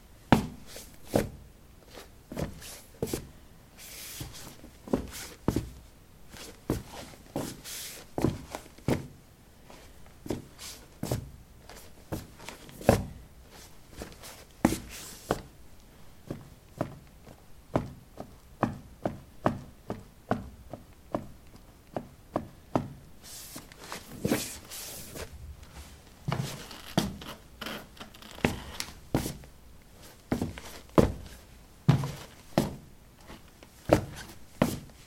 脚踏实地的瓷砖 " 陶瓷03B拖鞋洗脚水门槛
描述：在瓷砖上拖鞋：拖鞋。在房子的浴室里用ZOOM H2记录，用Audacity标准化。